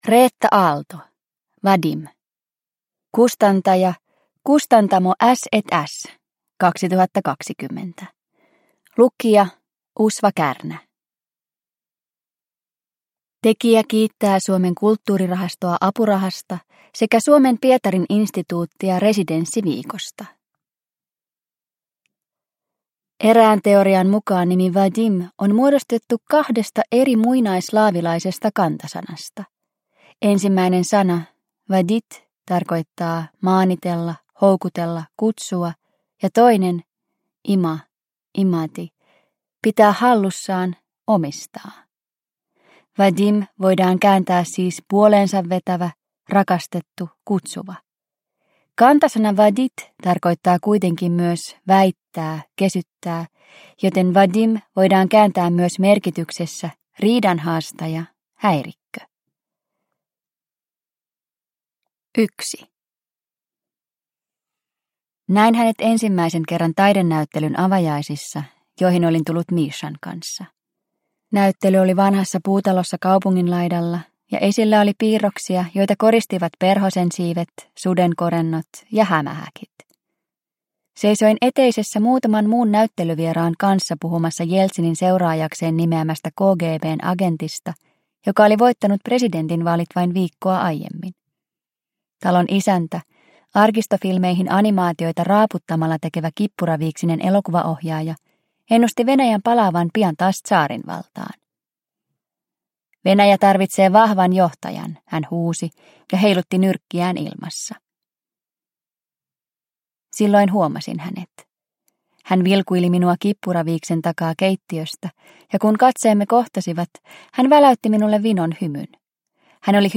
Vadim – Ljudbok – Laddas ner